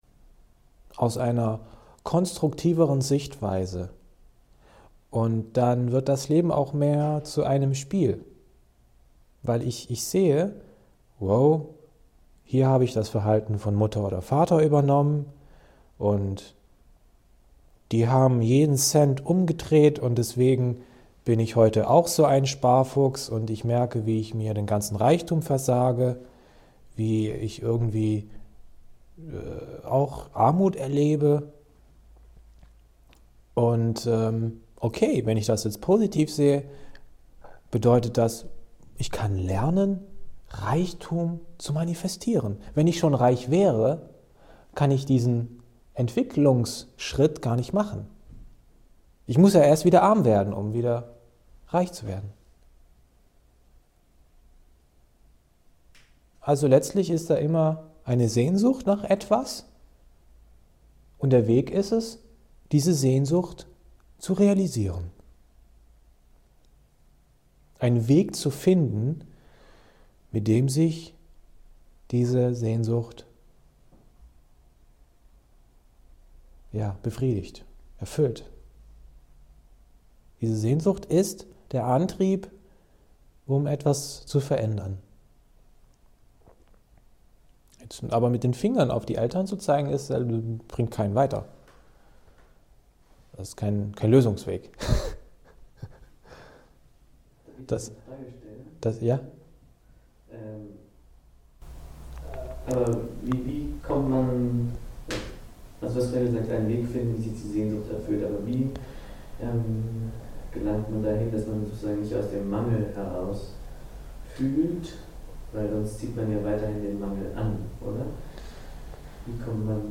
Willkommen! Hier findest du dein bestelltes Video, welches auf dem Winter Retreat 2017 entstanden ist.